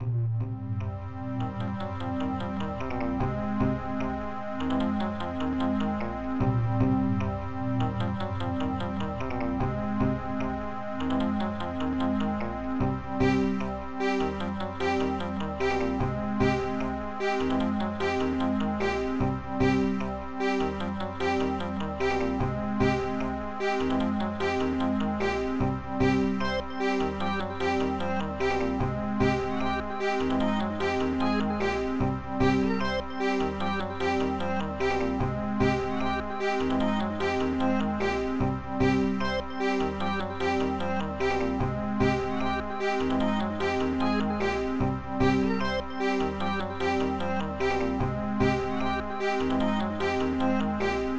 Protracker Module
Instruments FlangeBass3 FlangeBass4 Accordian